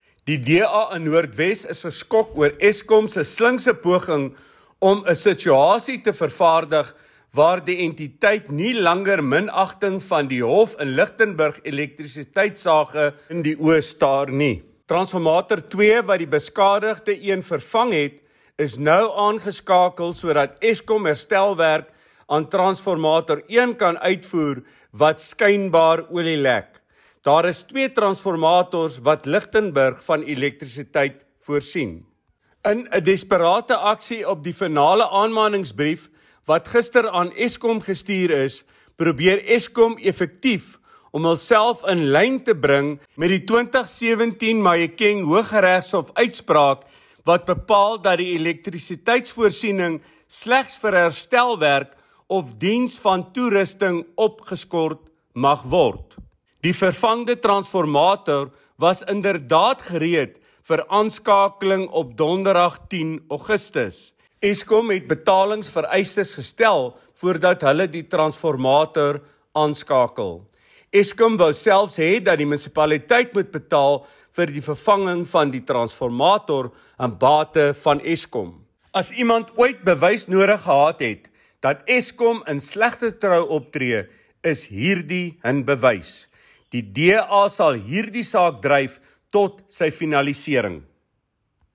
Note to Broadcasters: Please find linked soundbites in English and
Afrikaans by Leon Basson MP